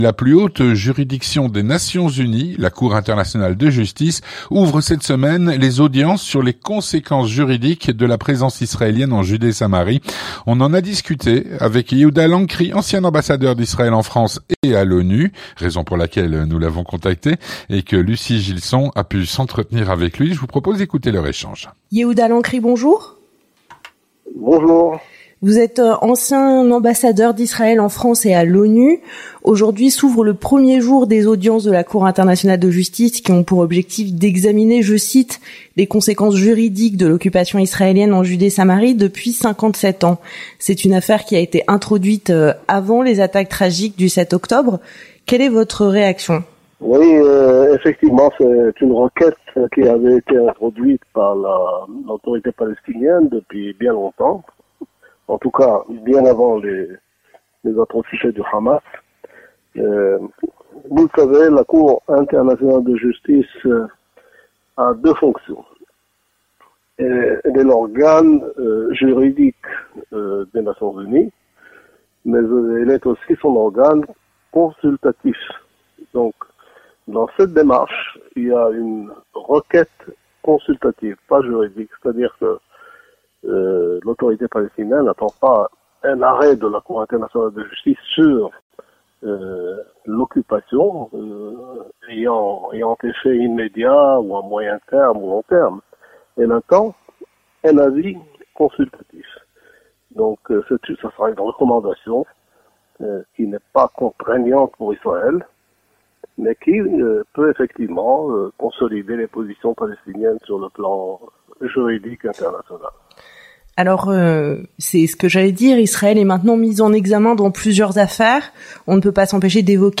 L'entretien du 18H - la Cour Internationale de Justice ouvre cette semaine les audiences sur les conséquences juridiques de la présence israélienne en Judée-Samarie.
Avec Yehuda Lancry, ancien ambassadeur d’Israël en France et à l’ONU.